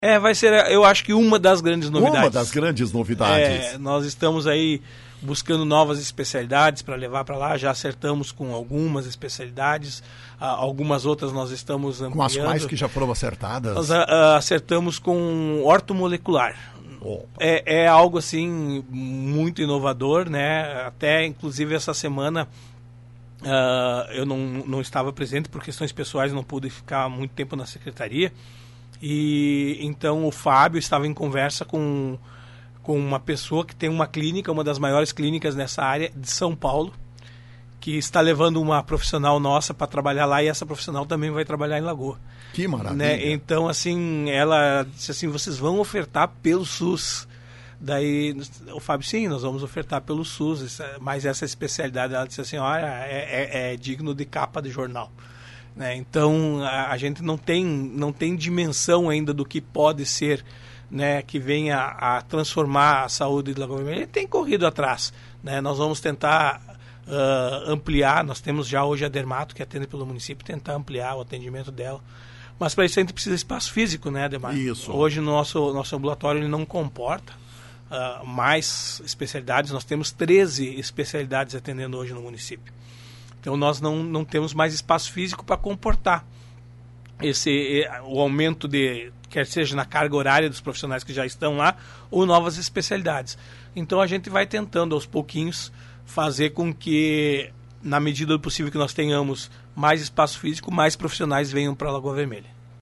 Uma informação importante transmitida pelo secretário municipal da Saúde, Eloir Morona, na manhã desta quinta-feira: Lagoa Vermelha passará a contar a especialidade Ortomolecular na área médica.